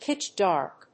アクセントpítch‐dárk